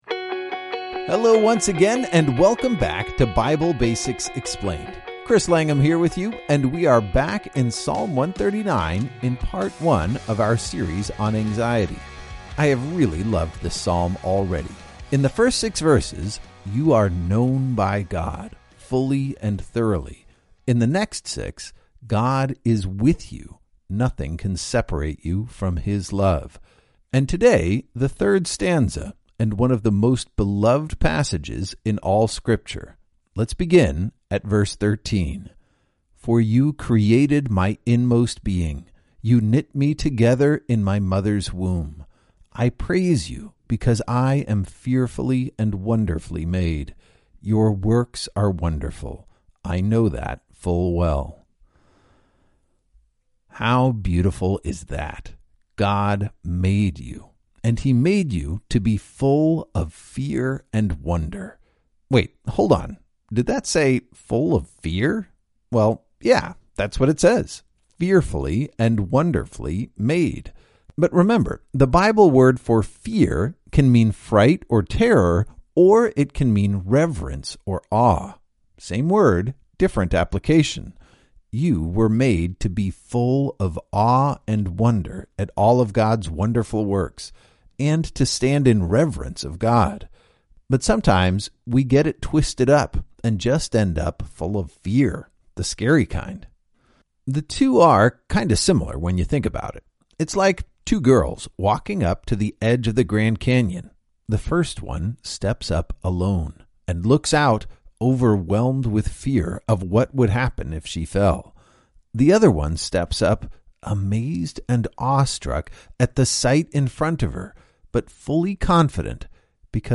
This devotional works best as an audio experience.
Audio guides by Through the Word.